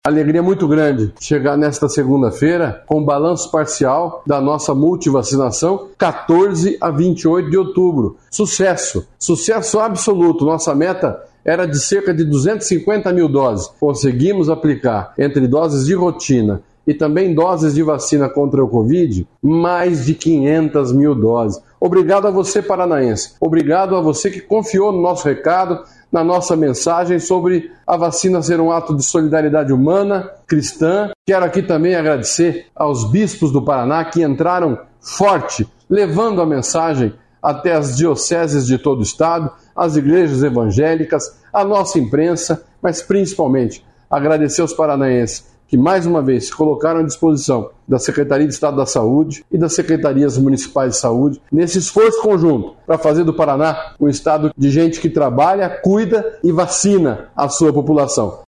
Sonora do secretário da Saúde, Beto Preto, sobre o Paraná ter dobrado a meta estipulada para campanha de multivacinação | Governo do Estado do Paraná
Sonora do secretário da Saúde, Beto Preto, sobre o Paraná ter dobrado a meta estipulada para campanha de multivacinação